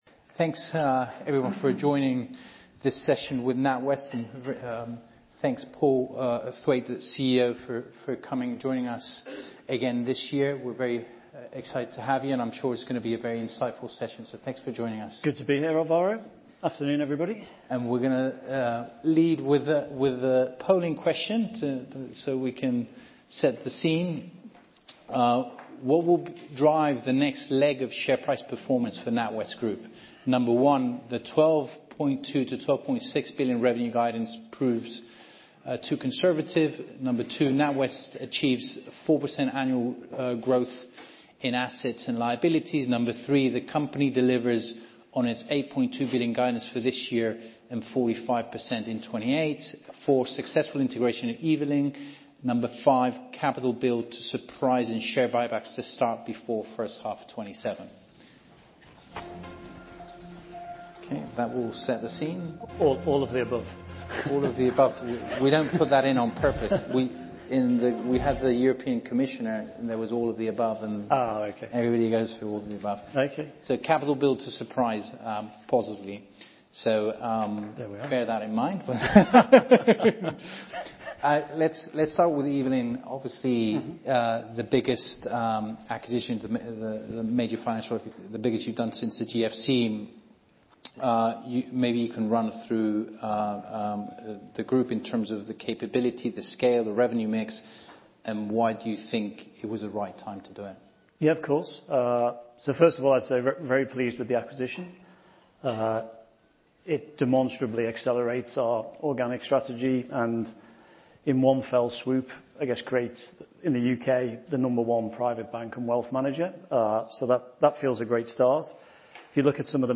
Fireside chat with Paul Thwaite
NatWest CEO Paul Thwaite hosted a fireside chat at Morgan Stanley European Financials Conference on Tuesday 17th March at 1.00pm GMT.